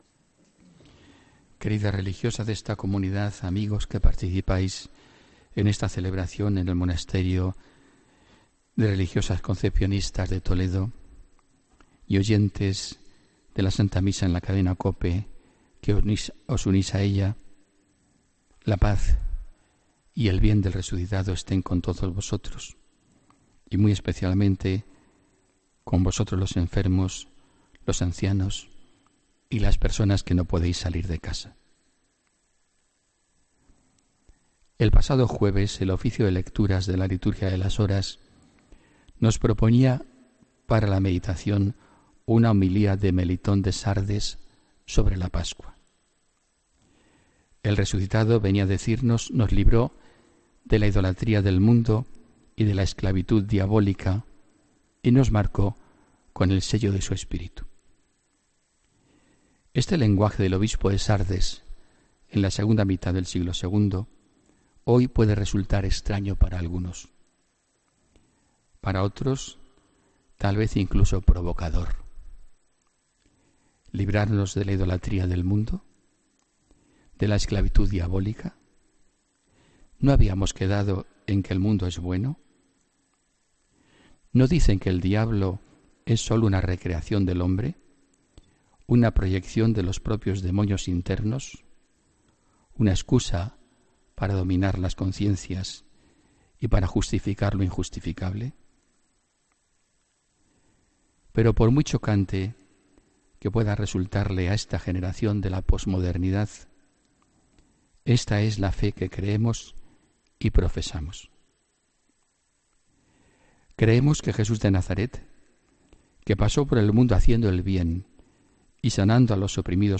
Homilía, domingo 16 de abril de 2017